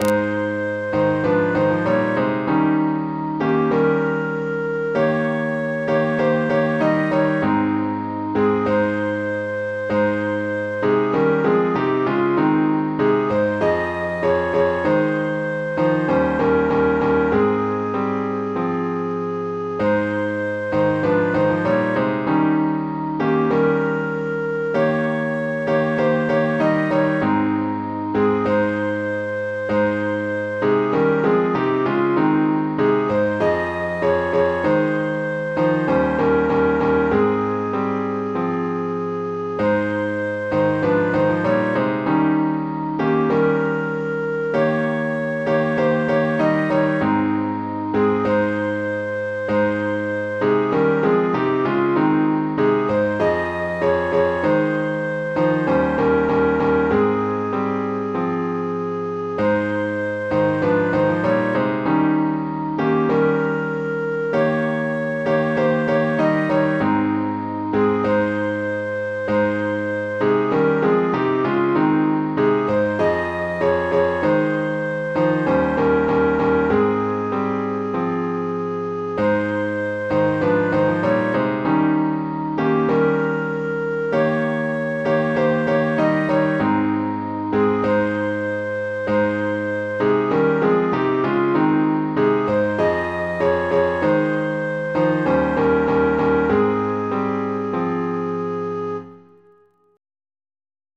piano, keyboard, keys
Мелодия за разучаване: